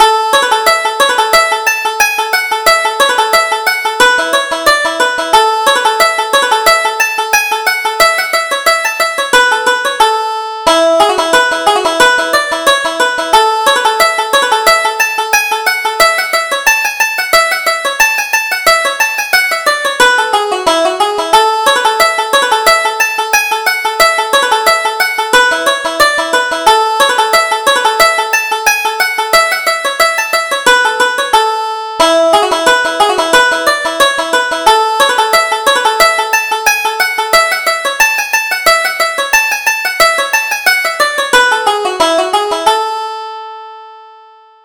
Reel: Peter Street